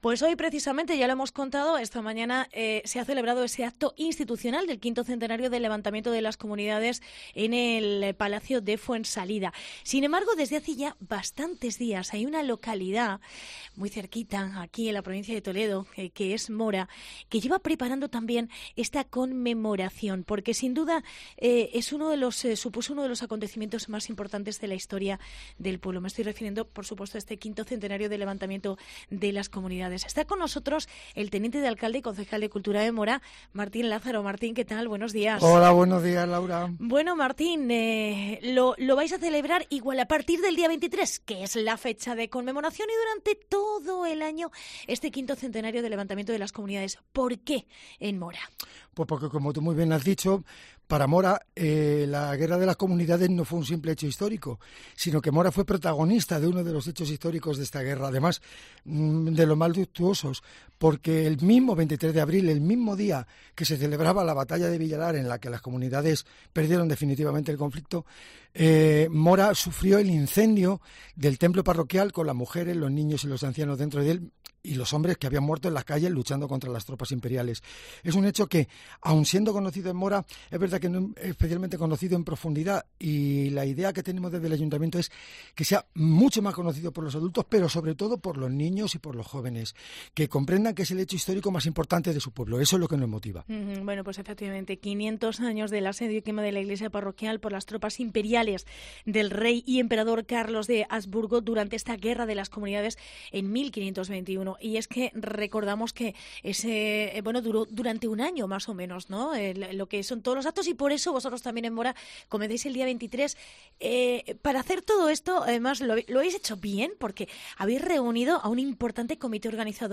Entrevista Martín Lázaro, concejal de cultura de MORA